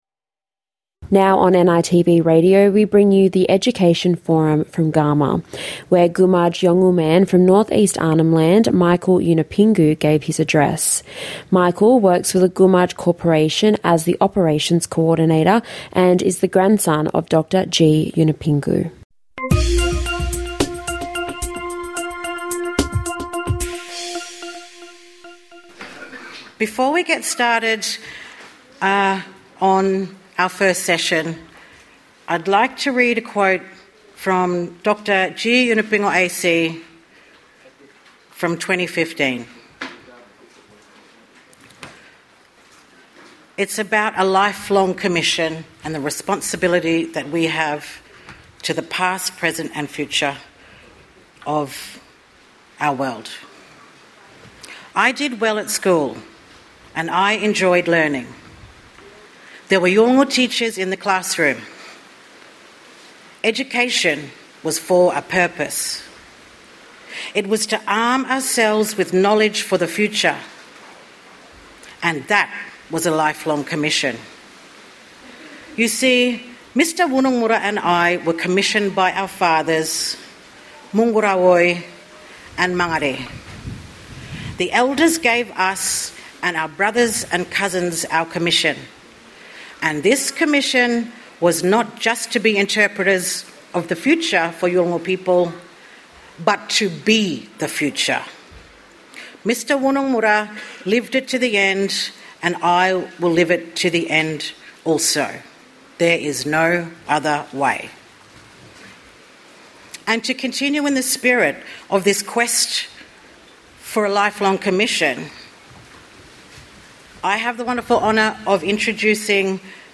NITV Radio brings you highlights from this years 2025 Garma Festival.